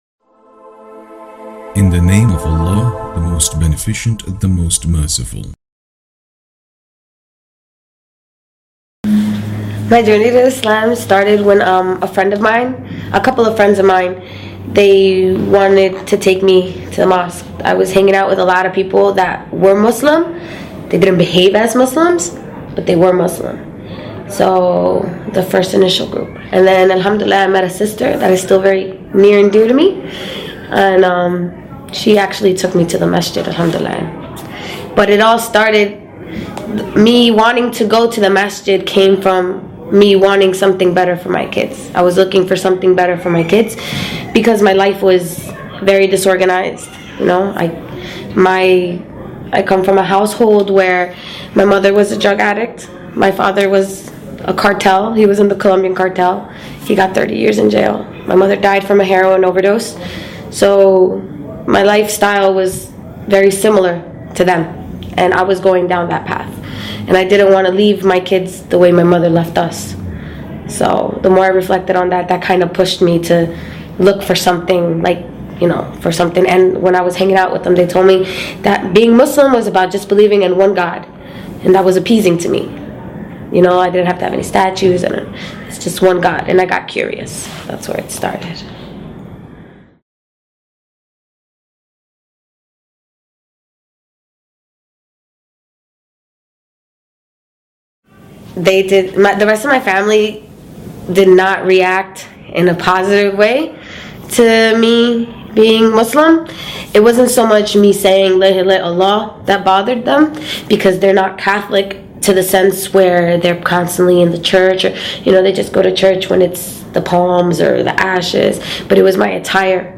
A revert recounts how her mother was a drug addict who died...